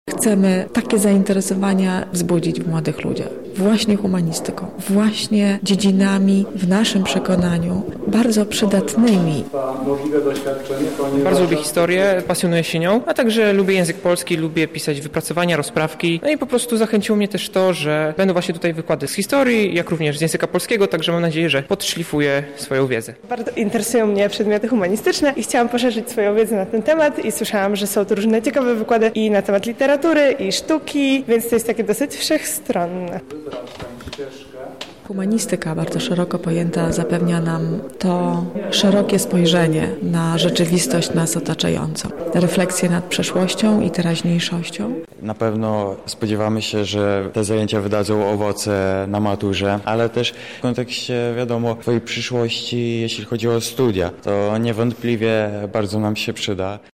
Młodym studentom towarzyszyła nasza reporterka: